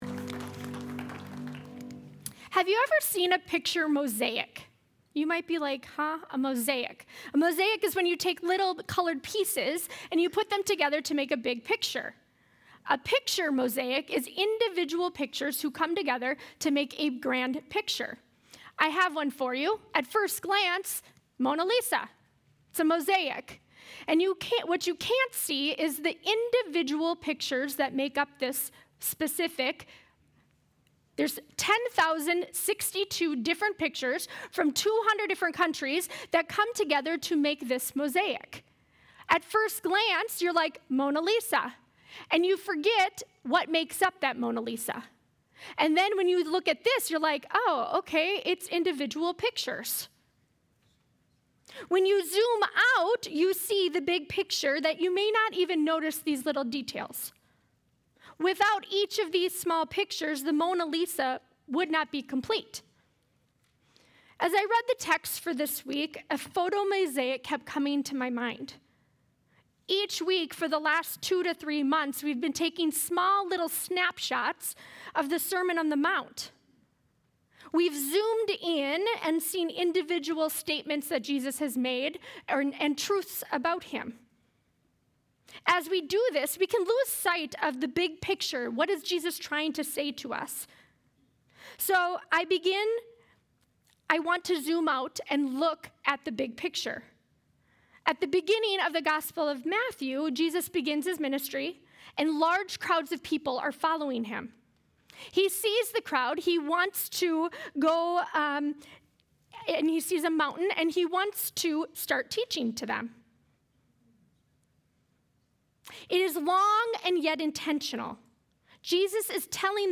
Sunday Sermon: 10-5-25